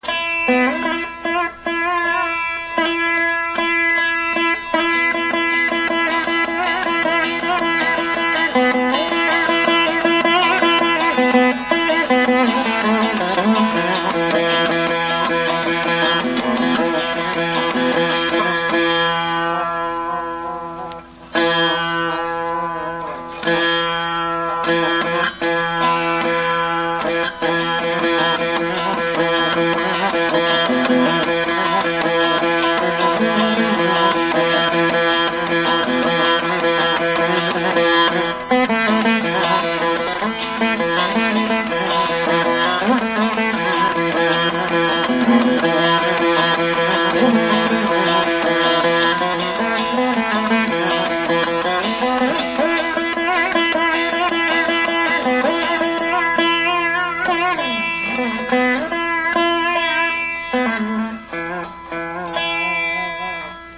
vīna